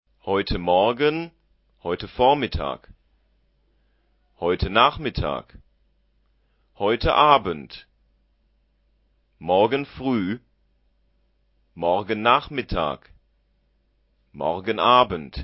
Click here to listen to the vocabulary being spoken!Why is "heute Morgen" used to translate "this morning" whereas "morgen früh" is used for "tomorrow morning"?